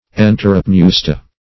Search Result for " enteropneusta" : The Collaborative International Dictionary of English v.0.48: Enteropneusta \En`te*rop*neus"ta\, n. pl.